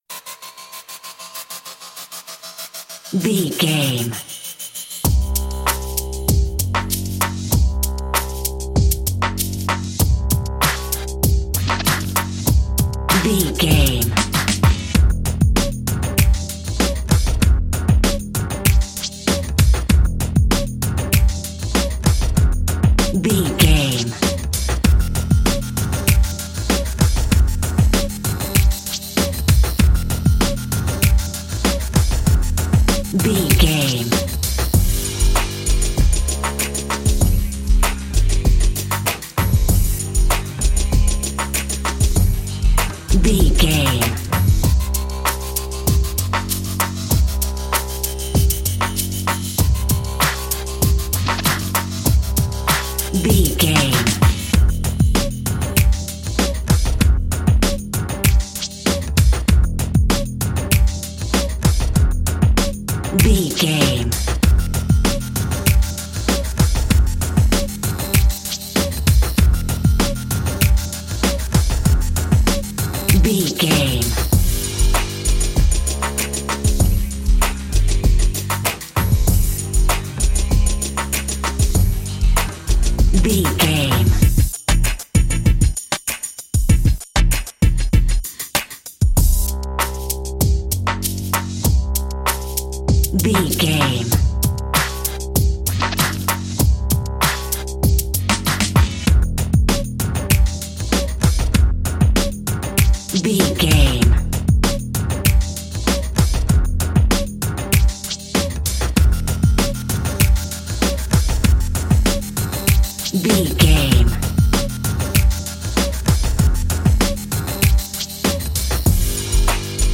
Aeolian/Minor
synthesiser
drum machine
hip hop
soul
Funk
acid jazz
energetic
cheerful
bouncy
funky